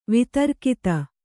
♪ vitarkita